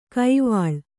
♪ kaivāḷ